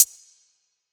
TS - HAT (7).wav